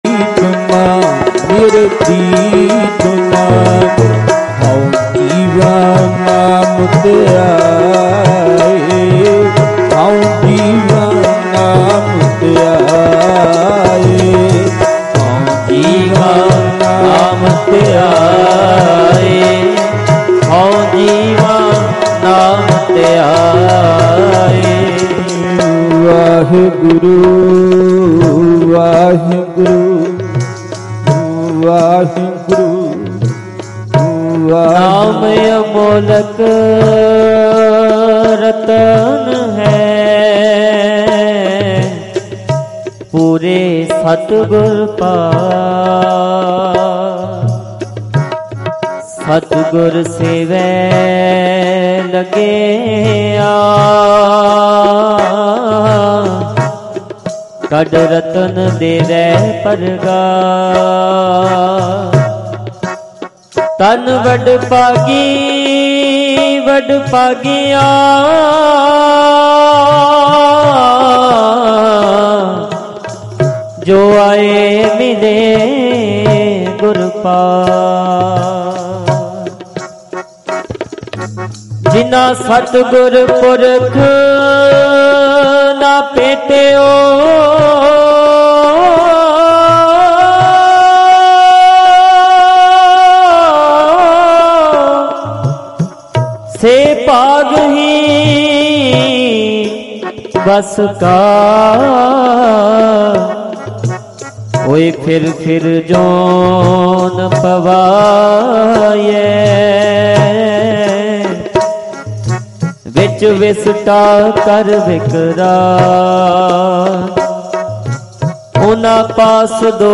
Live Samagam Sakhi bhai Maha Singh Maghi History Bhawanigarh 11 jan 2026 Dhadrian Wale | DhadrianWale Diwan Audios mp3 downloads gurbani songs